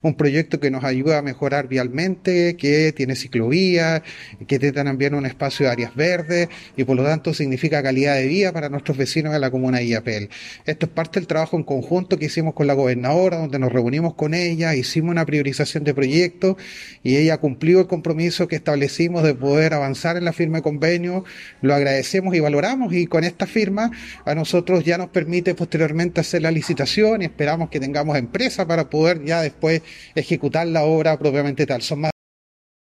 Por su parte, el alcalde Cortés afirmó que